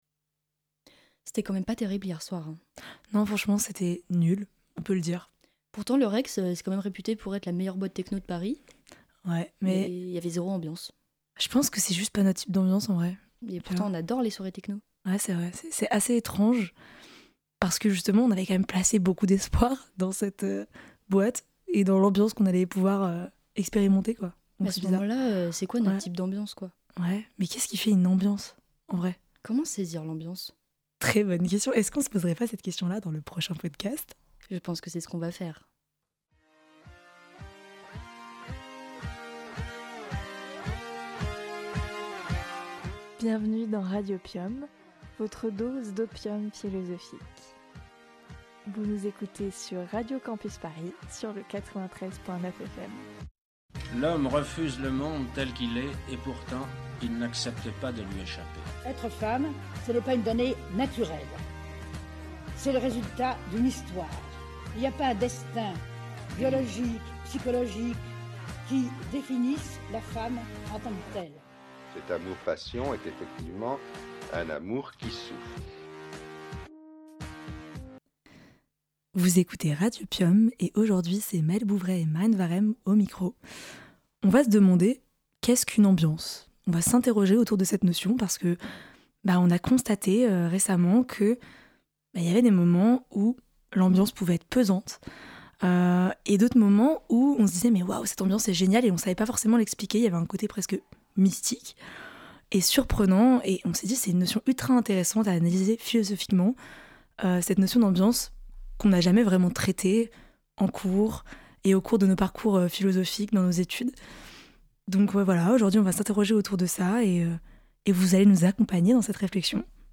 En prime, nous y avons glissé une chanson qui, assurément, nous ambiance.